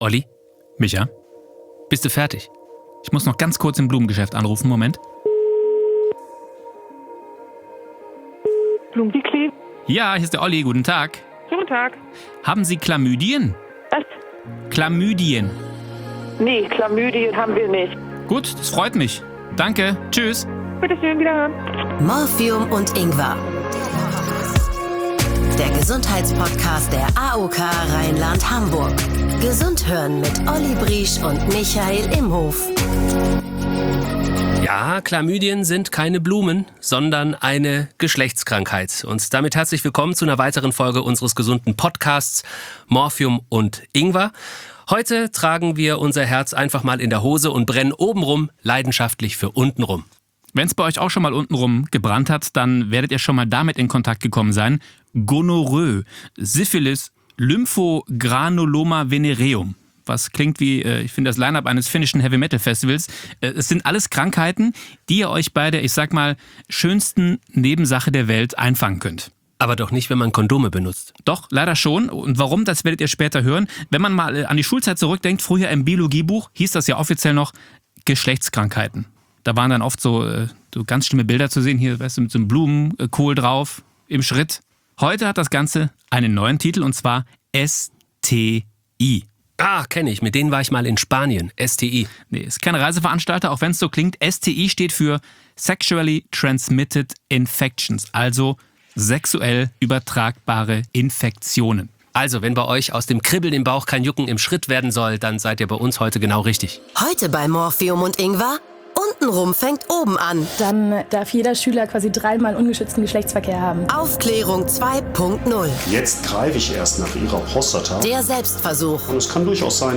Zwei der bekanntesten Radiomoderatoren Deutschlands, Olli Briesch und Michael Imhof, liefern euch alle zwei Wochen alles zu einem Gesundheitsthema, Experten und machen den Selbstversuch.